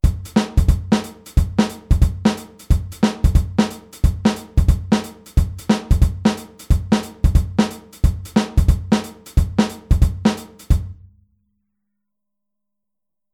Der Handsatz beim Shufflen
Groove23-24tel.mp3